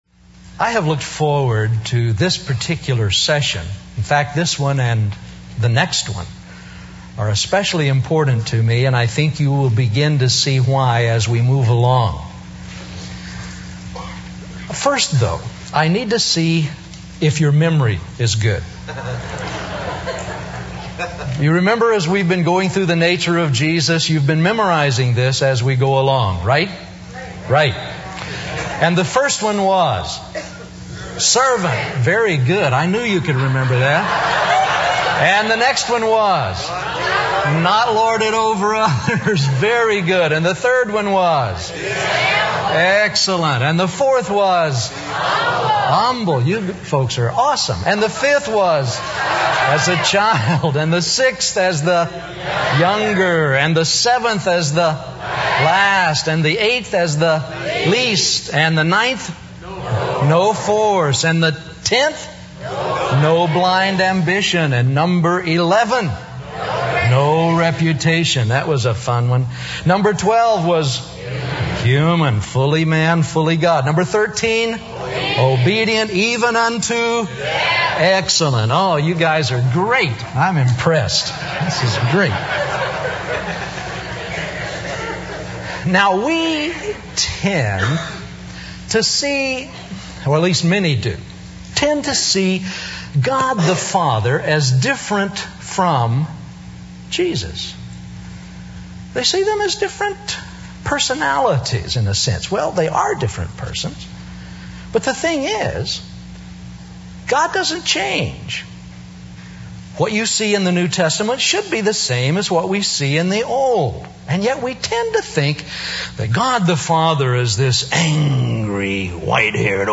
In this sermon, the preacher emphasizes that all humans are lawbreakers and cannot keep the law.